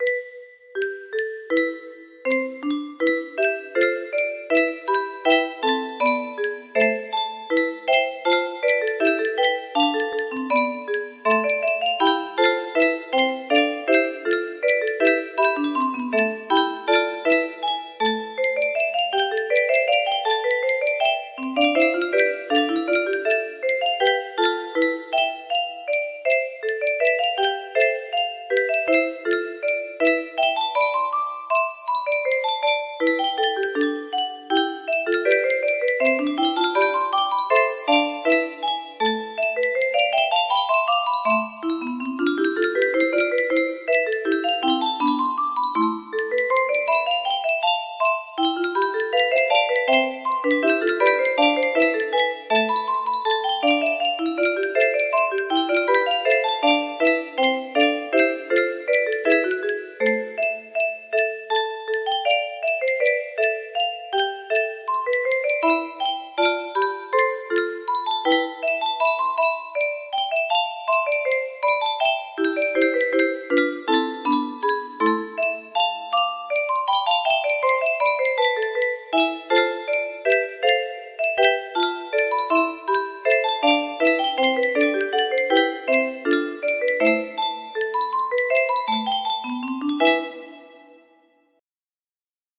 BASSANO, Giovanni - Ricercar XI music box melody